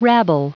Prononciation du mot rabble en anglais (fichier audio)
Prononciation du mot : rabble